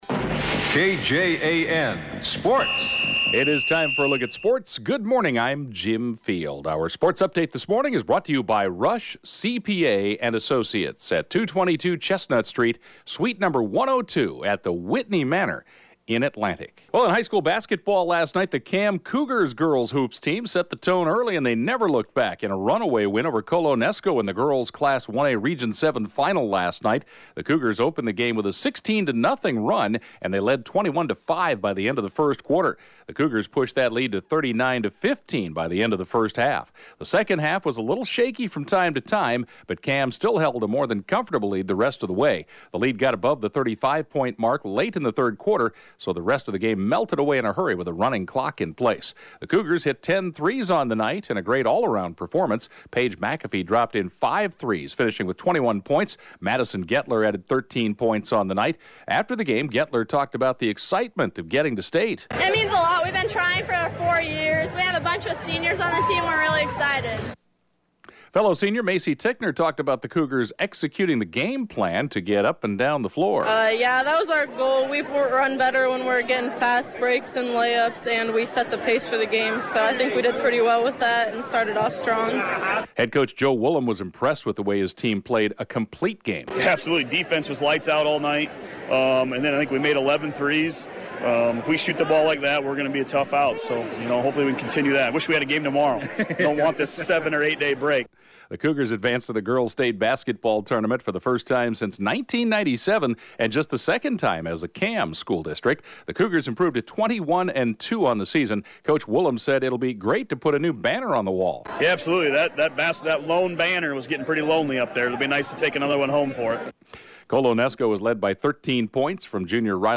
The 7:20-a.m. Sportscast